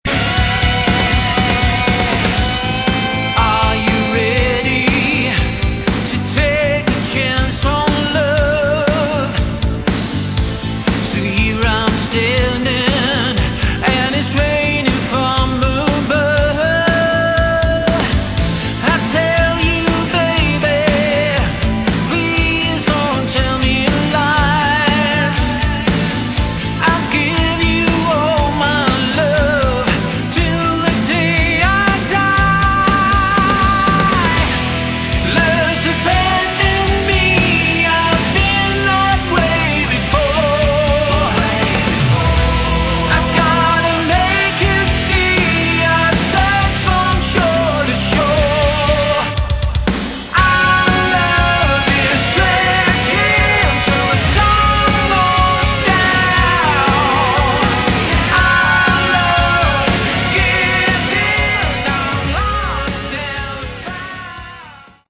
Category: Melodic Rock
guitar
vocals
bass
keyboards
drums